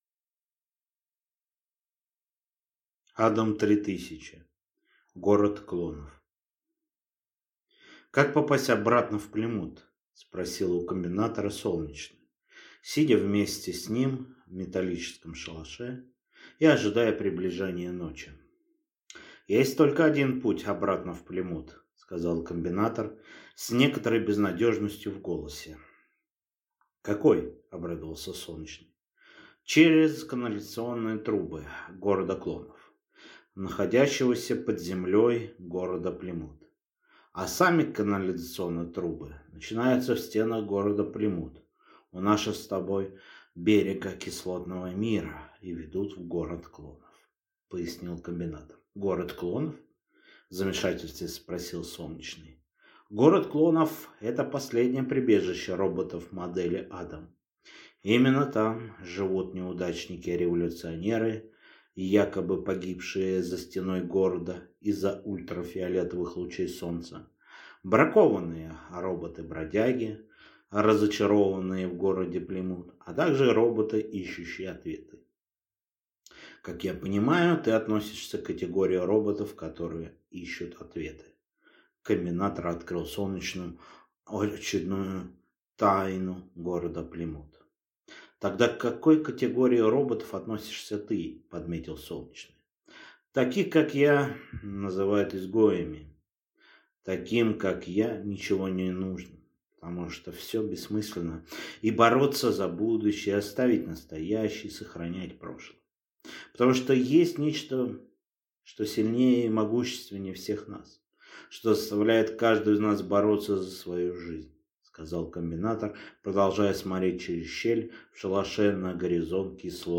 Аудиокнига Адам-3000. Город клонов | Библиотека аудиокниг